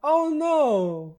Add voiced sfx
ohno3.ogg